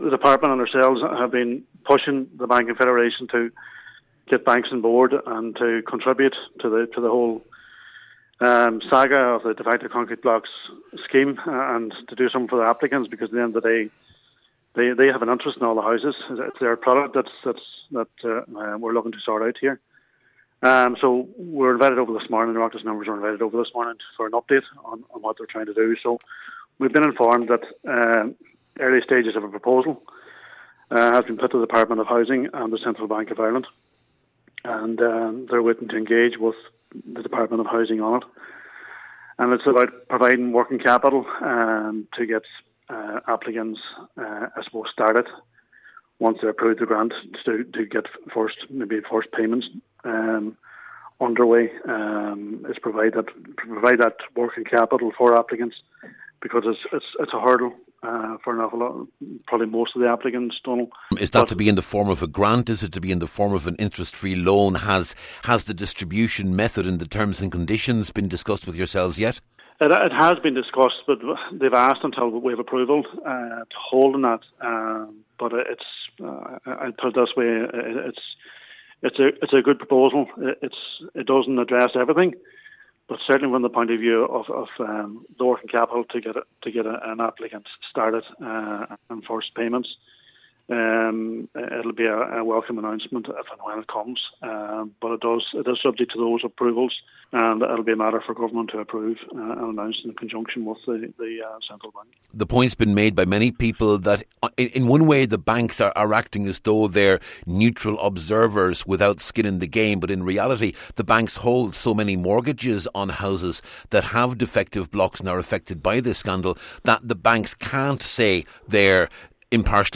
Senator Niall Blaney was at the meeting – He says this is potentially a very important development, but there is much yet to be done……